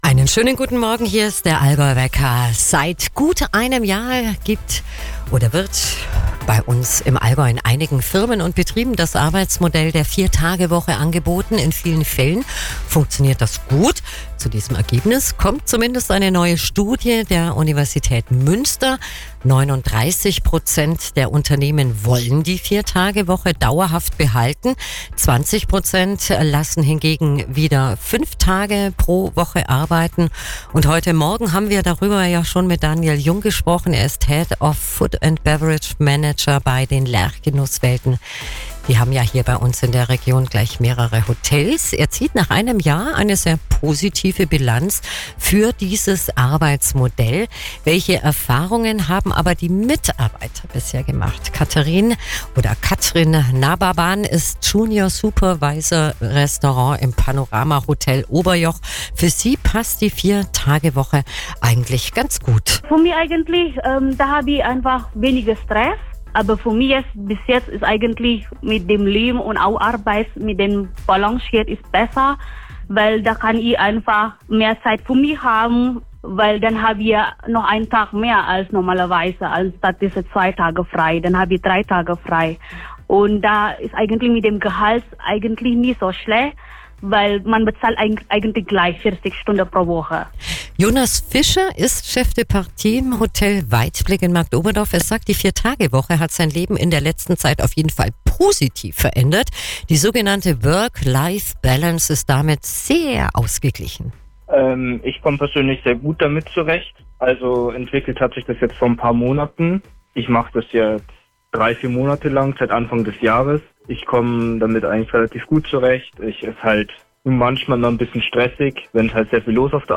02. Juni 2025: Die Lerch Genusswelten waren zu Gast bei Radio AllgäuHit.